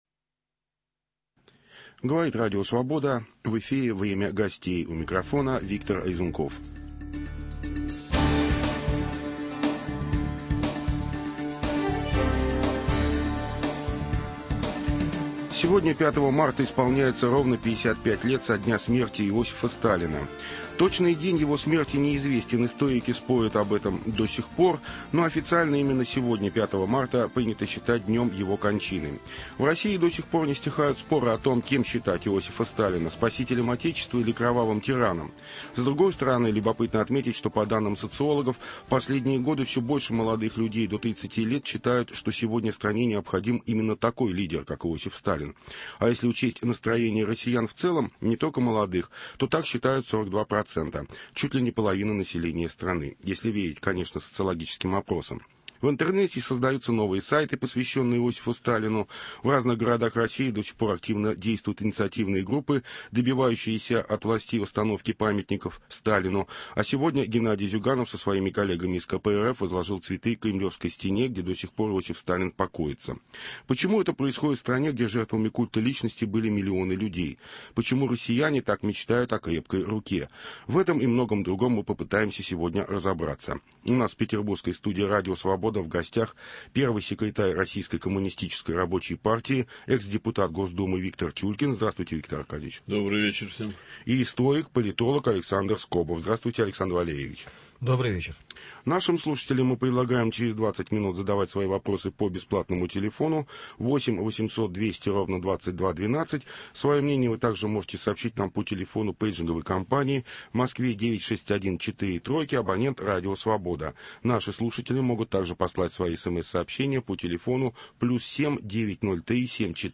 Сталинизм и Россия сегодняшняя... Обсуждают